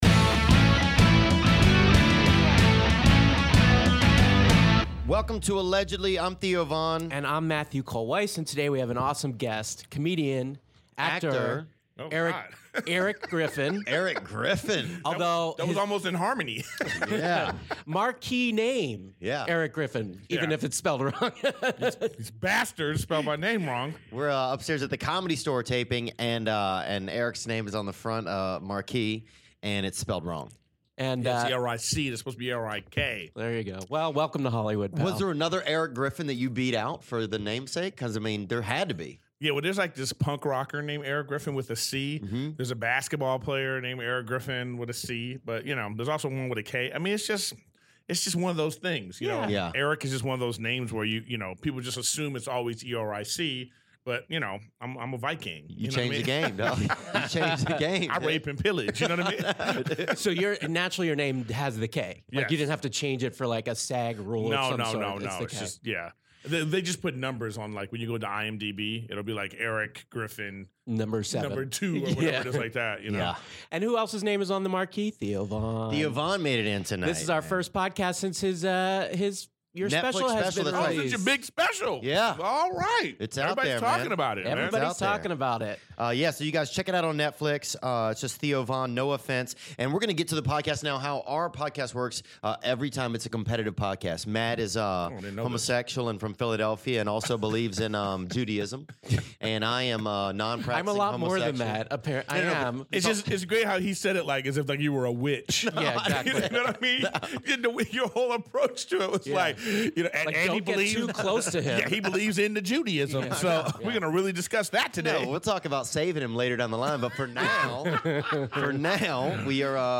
It's Erik Griffin, star of Comedy Central's "Workaholics", in studio and ready to dish like a school girl.